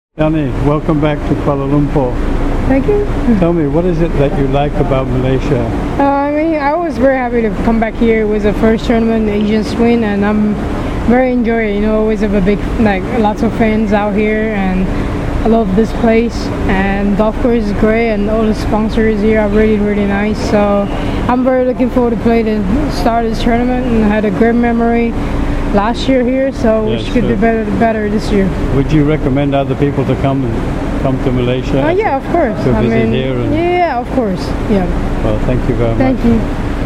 MGTA interviews Yani Tseng